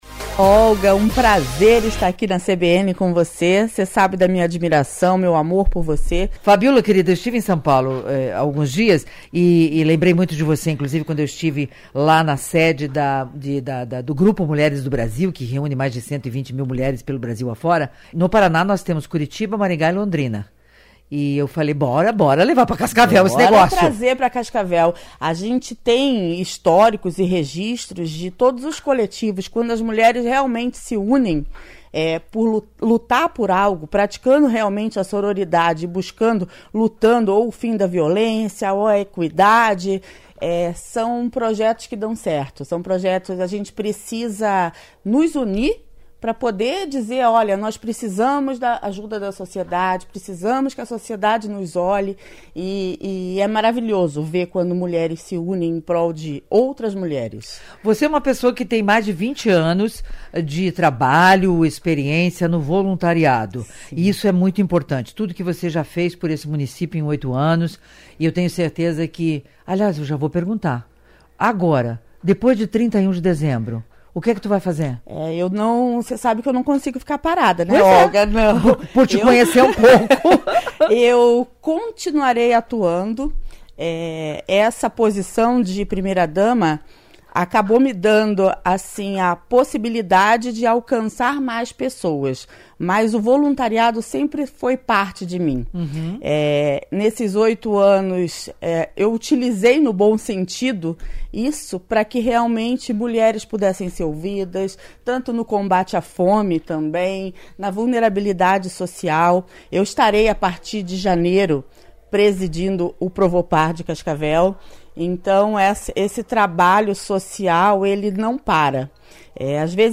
Em entrevista ao Revista CBN deste sábado (16)